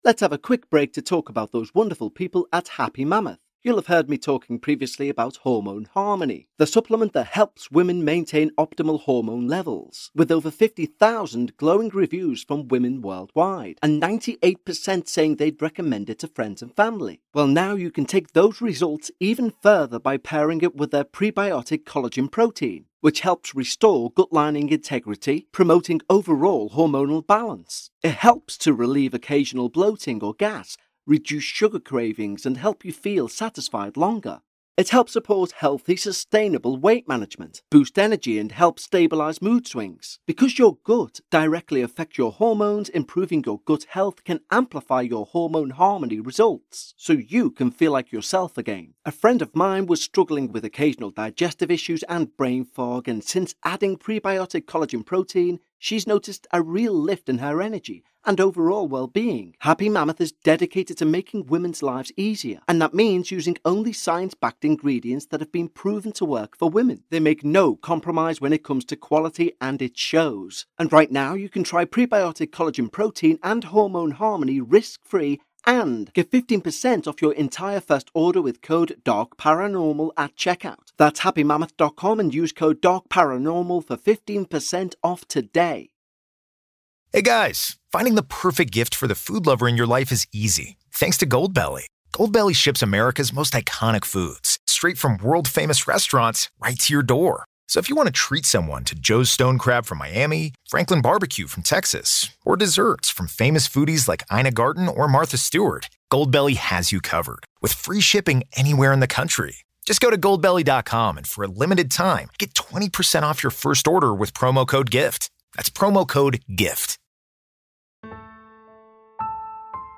Welcome, to our Pre-Season show for Season 22.